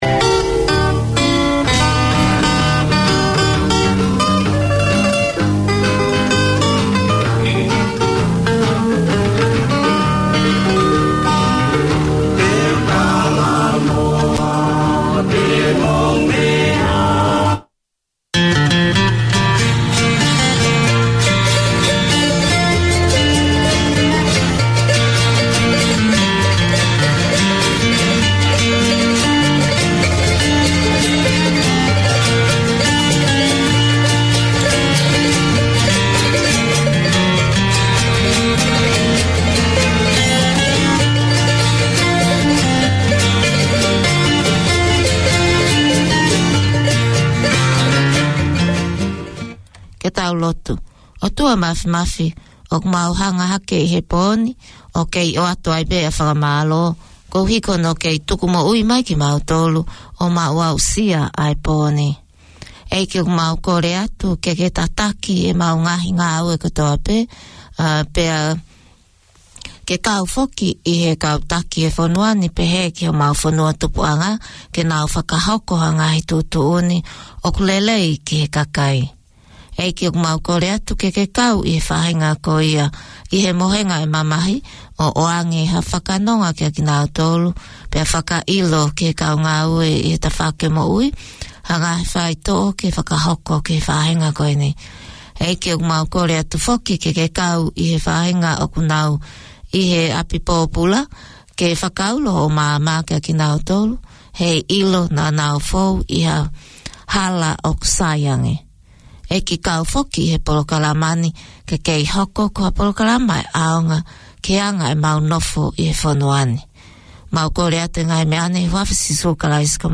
It has grown to include many Tongan community interests. The old and new mix of Tongan music is popular as are the education updates, health information, positive parenting segments, local and Canberra news bulletins. A short devotion opens each 2 hour programme.